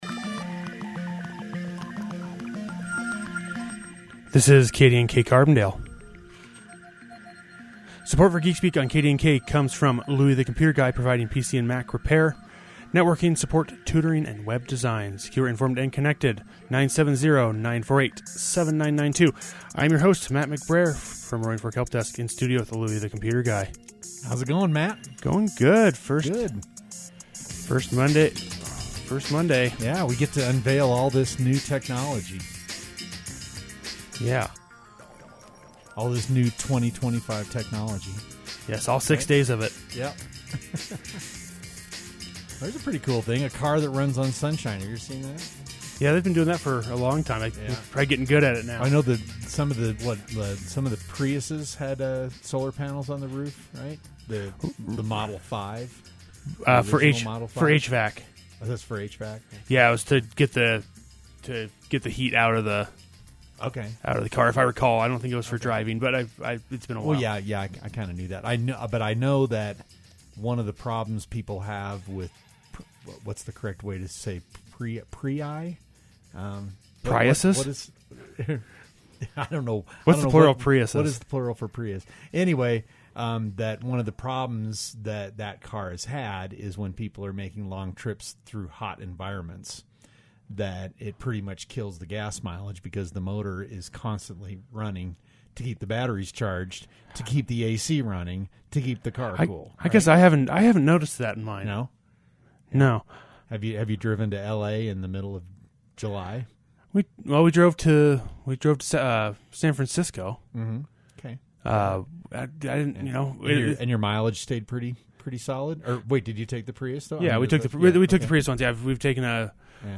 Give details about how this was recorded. Geekspeak airs on the first and third Monday of every month of KDNK at 4:30pm.